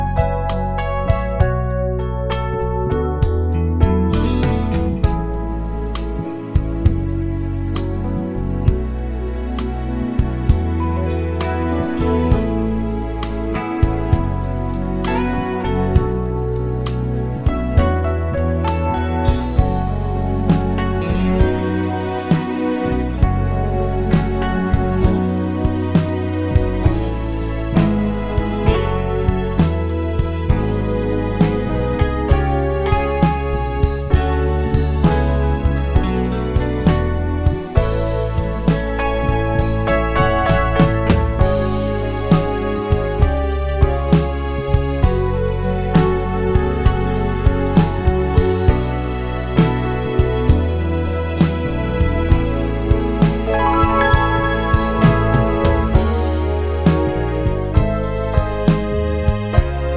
no sax or Backing Vocals Pop (1980s) 4:00 Buy £1.50